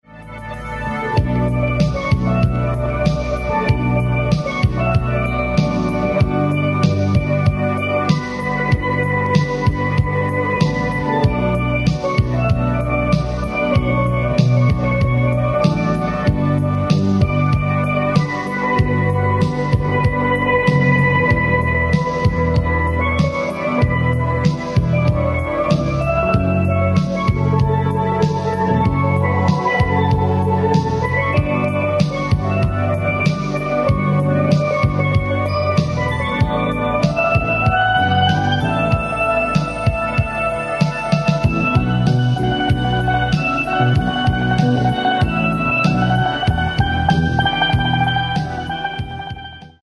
• Authentic Caribbean steel pan band